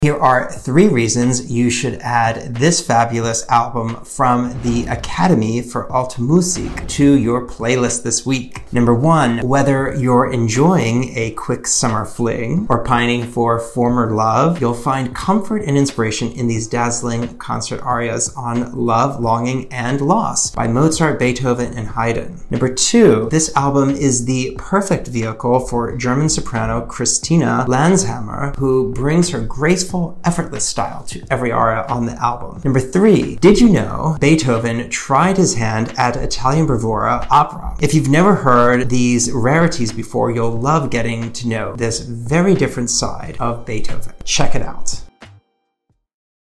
some of the most passionate music
Classical era